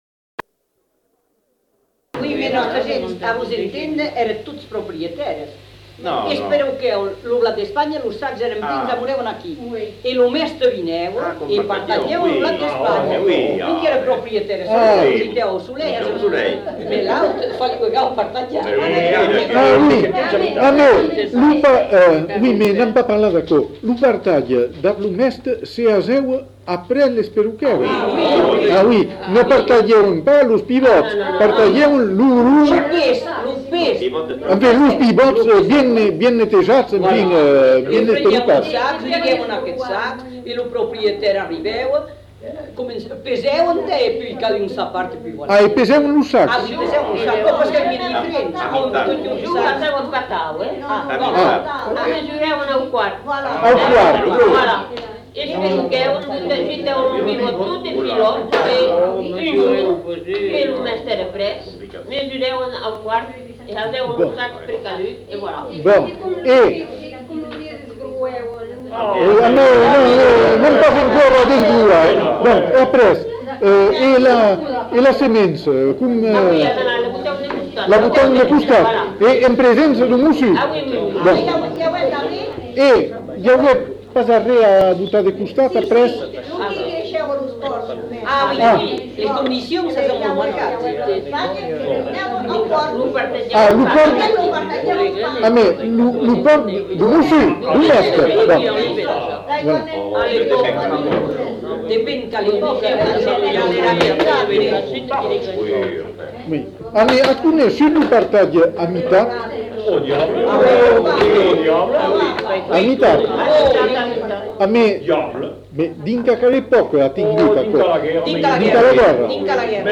Lieu : Bazas
Genre : témoignage thématique